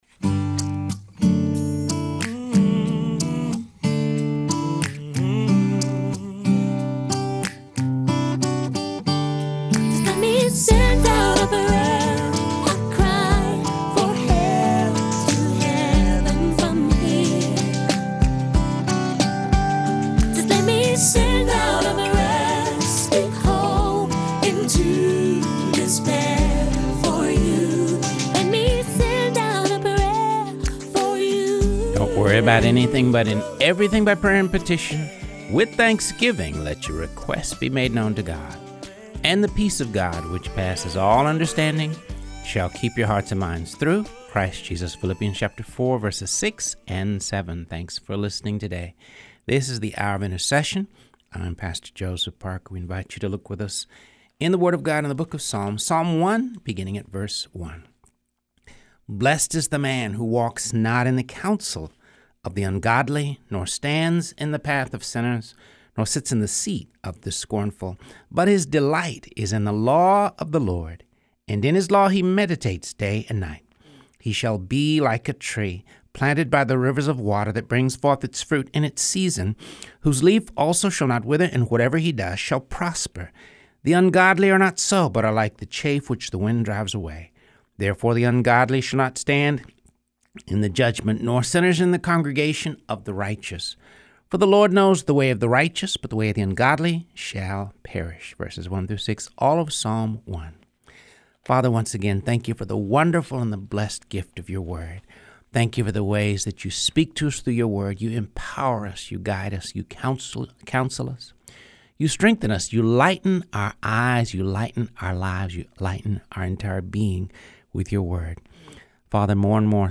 Reading through the Word of God | Episode 89